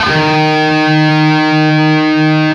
LEAD D#2 CUT.wav